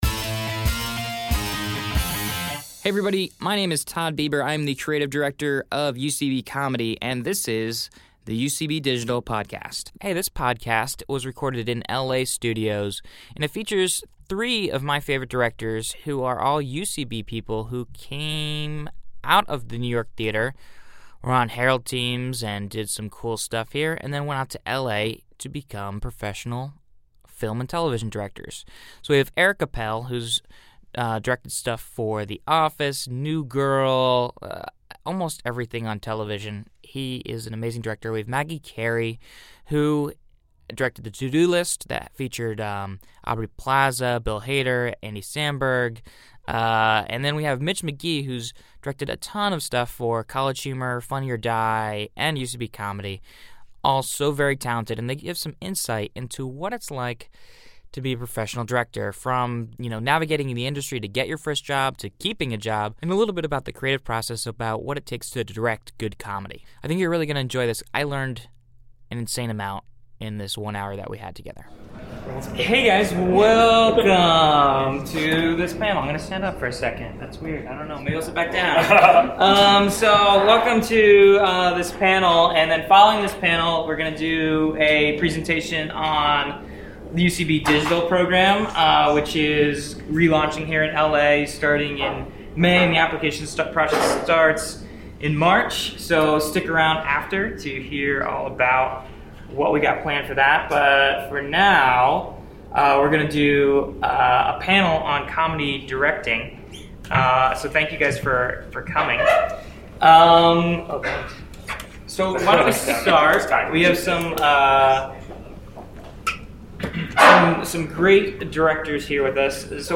Recorded live at UCB studios in LA.